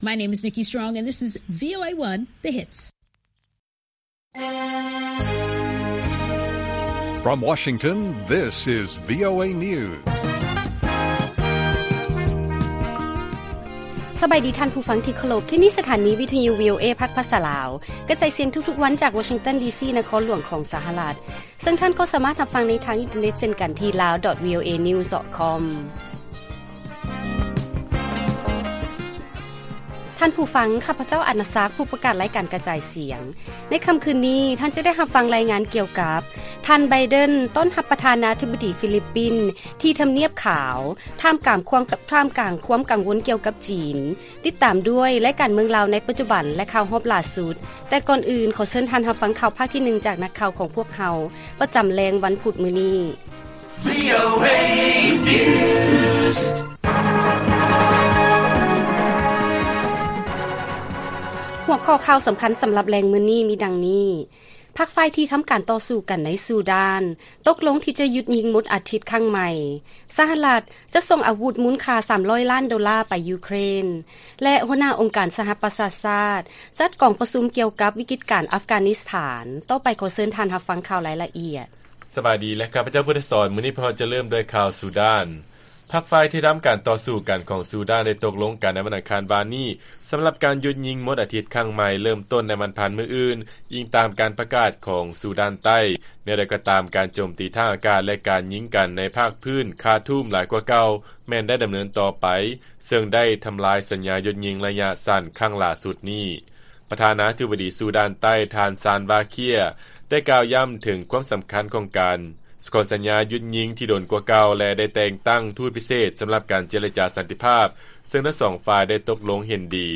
ລາຍການກະຈາຍສຽງຂອງວີໂອເອ ລາວ: ພັກຝ່າຍທີ່ທຳການຕໍ່ສູ້ກັນ ໃນ ຊູດານ ຕົກລົງທີ່ຈະຢຸດຍິງໝົດອາທິດ ຄັ້ງໃໝ່